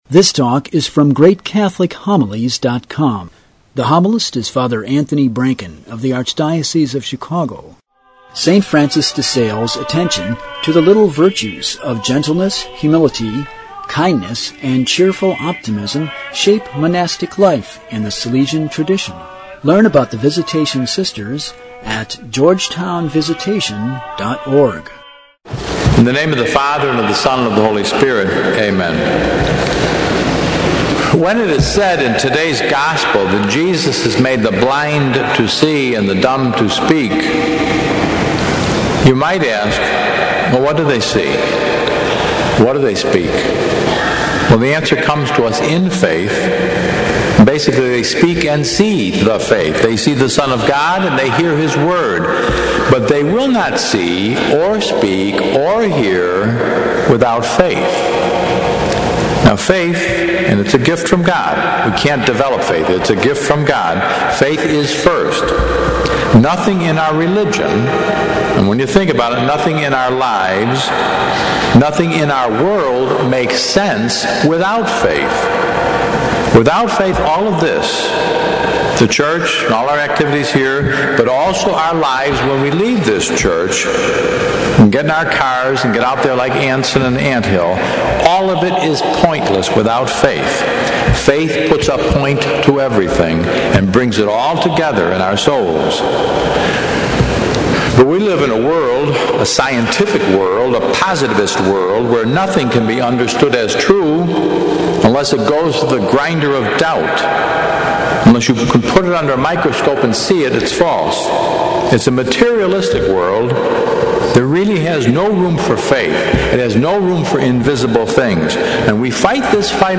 Daily Mass fills the hole in our hearts - Great Catholic Homilies